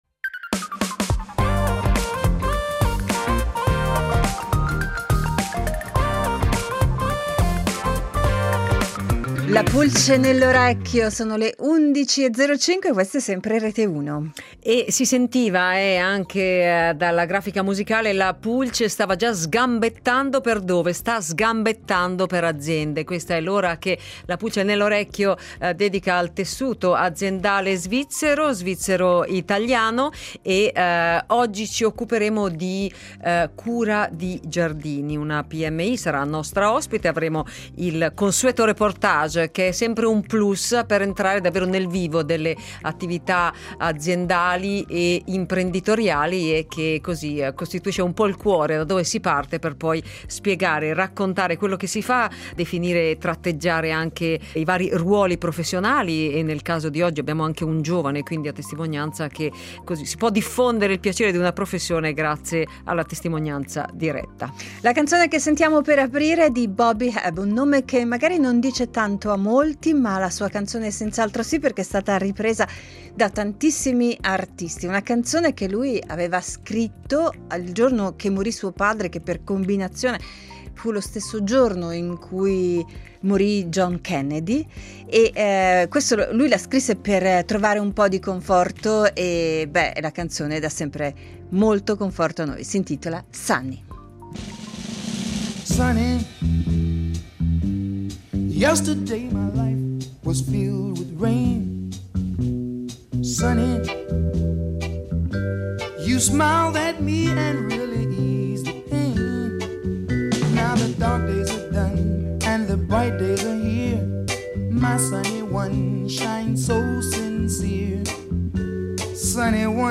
Dopo il reportage realizzato seguendo i lavori durante una giornata di tardo autunno, quando gli interventi si rivolgono soprattutto a potature, rimozione di fogliame e regolazione delle siepi, incontriamo in studio